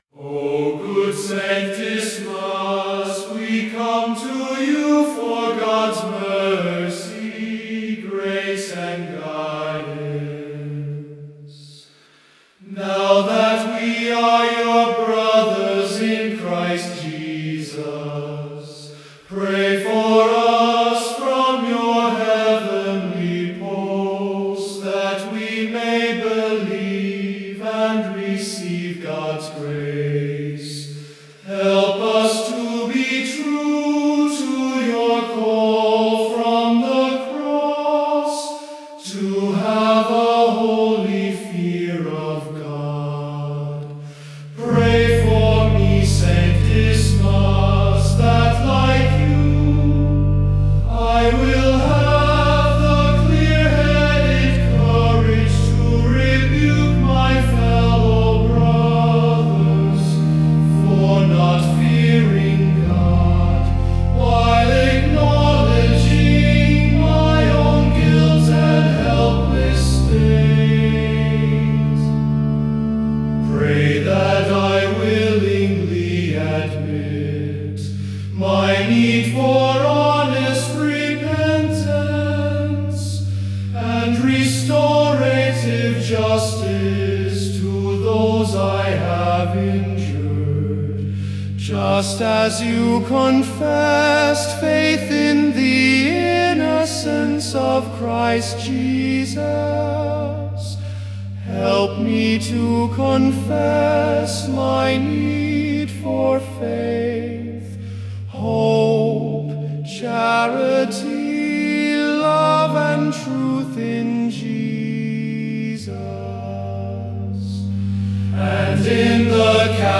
Gregorian Chant